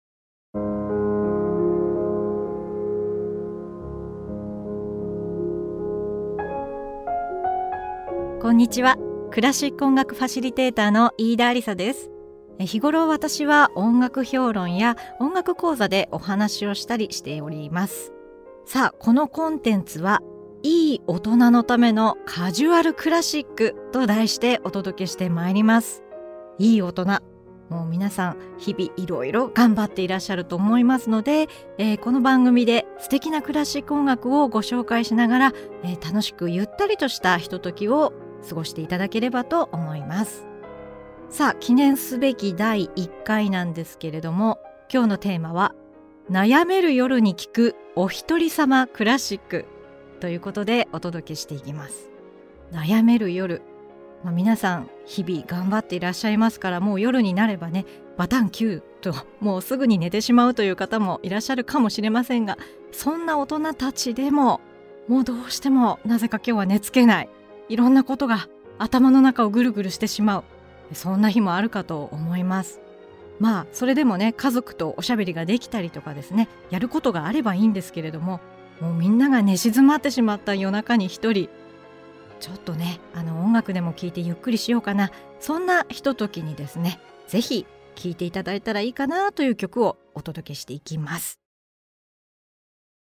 そんな夜を心安らかに過ごしていただこうという気持ちで選んだクラシック音楽を、作品の背景を楽しく紹介しながらお届けします。